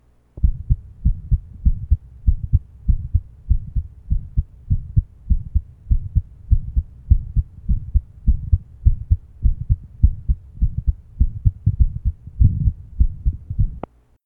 HeartSounds Project Audio Player